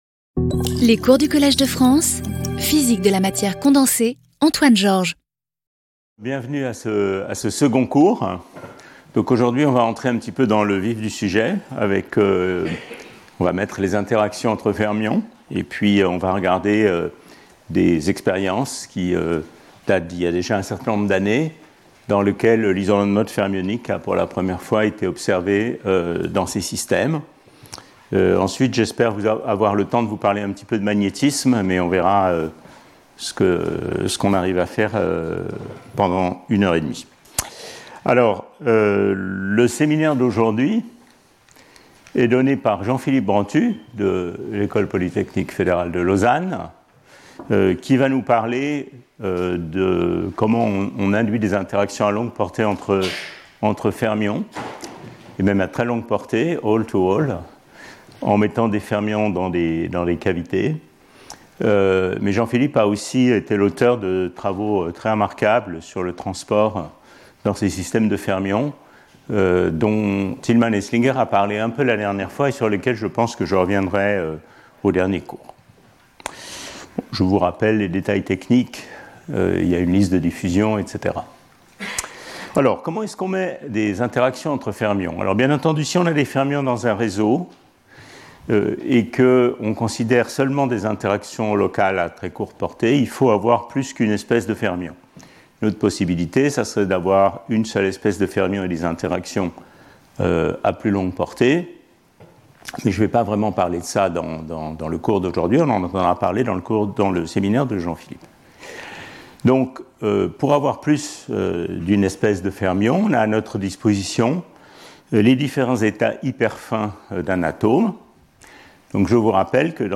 Intervenant(s) Antoine Georges Professeur du Collège de France